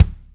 kick 3.wav